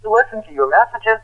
破碎的机器人拨号器消息 " 昆虫的鸣叫声
描述：这是与垃圾邮件块相同的音频，但加速了6次并重复。可能是一个方便可怕的昆虫声音效果。在Audacity中处理，我意外地发现复制和粘贴到新项目可能会导致6倍的音高增加。
标签： 怪异 消息 发现 手机 机器 故障 事故 噪音
声道立体声